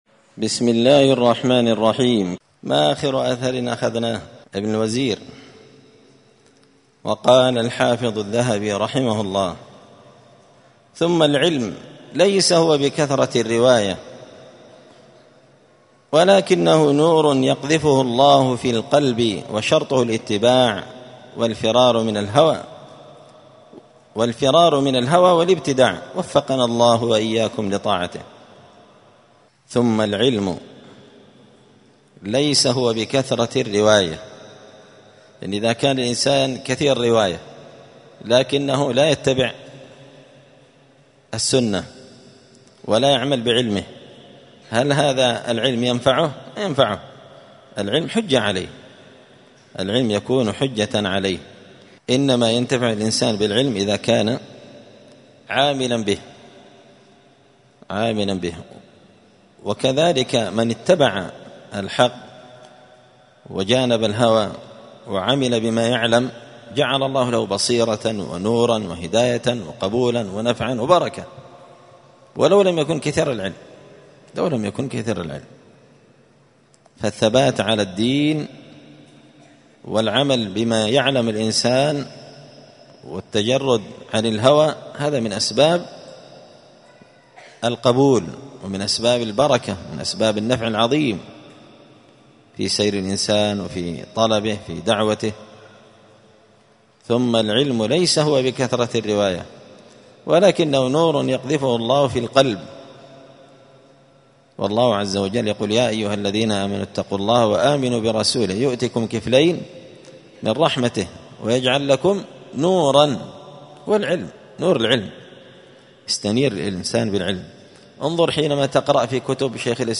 دار الحديث السلفية بمسجد الفرقان بقشن المهرة اليمن
الجمعة 2 جمادى الآخرة 1445 هــــ | الدروس، الفواكه الجنية من الآثار السلفية، دروس الآداب | شارك بتعليقك | 50 المشاهدات